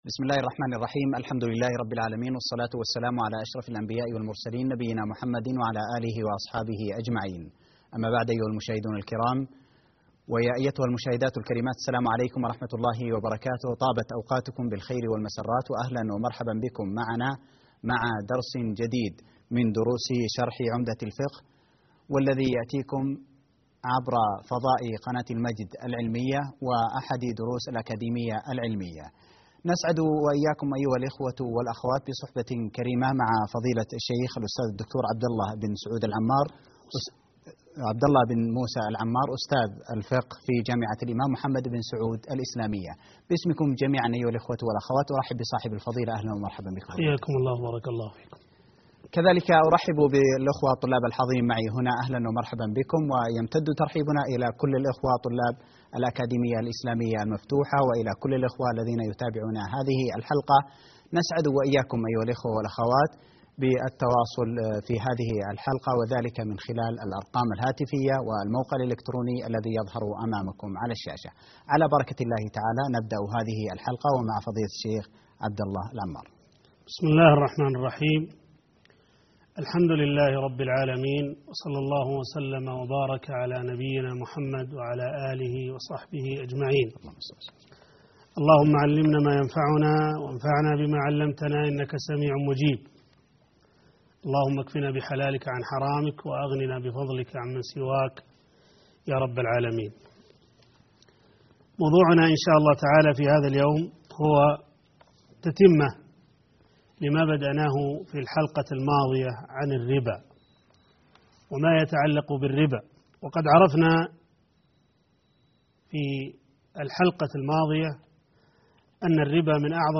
الدرس 6_ تتمة لموضوع الربا